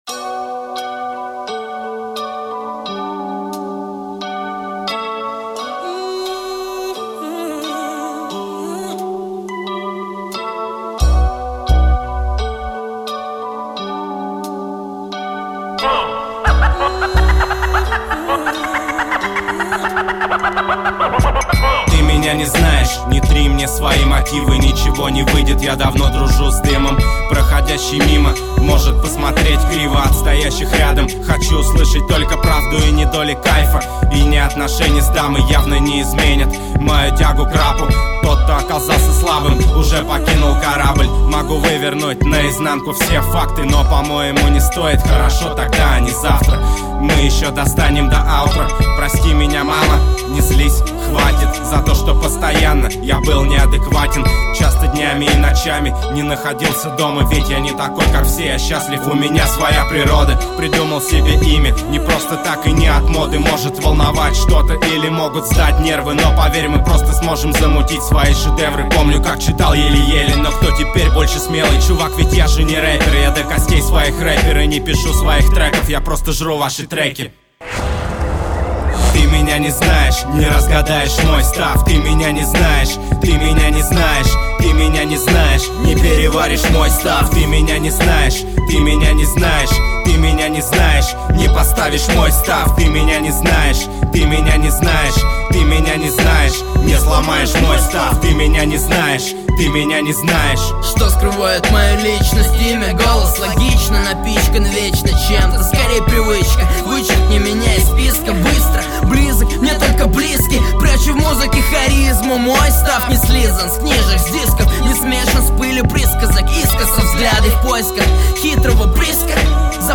2007 Рэп